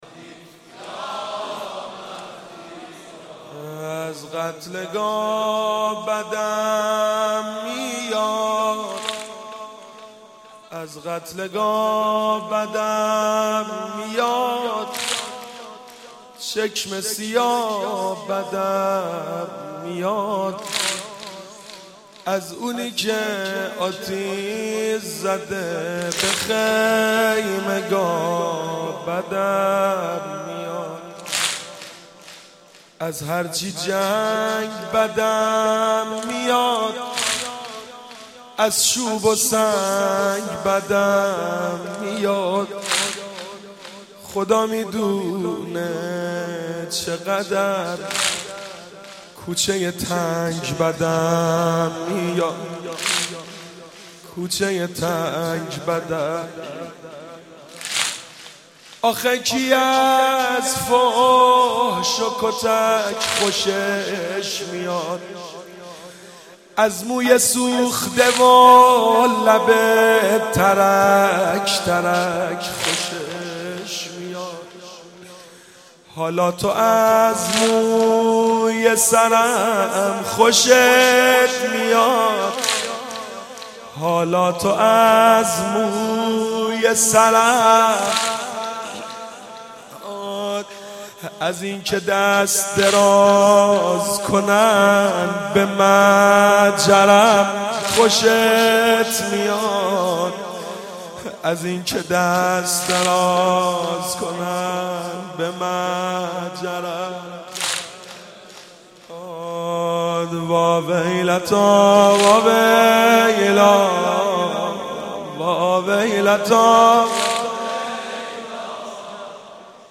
عقیق:شب عاشورا محرم95/هیئت غریب مدینه امیر کلا(بابل)
شور/وقتی که می گم یا حسین(ع)